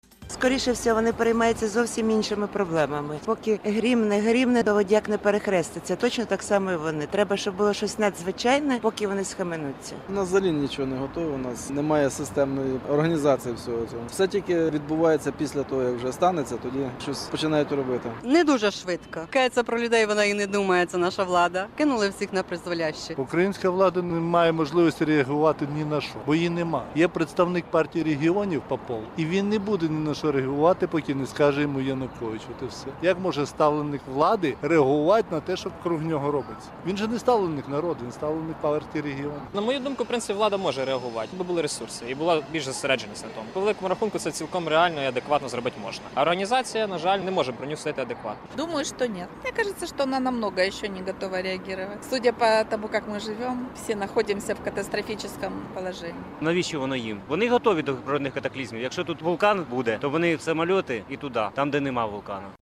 Опитування